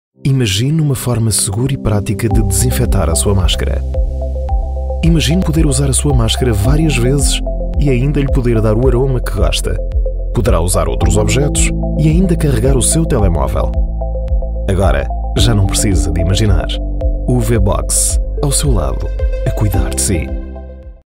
Commerciale, Naturelle, Polyvalente, Fiable, Amicale
Vidéo explicative
Calm, deep recording, aggressive sales voice, lively, friendly, conversational.